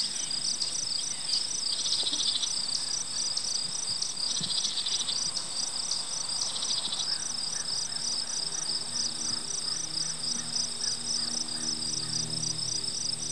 forest1.wav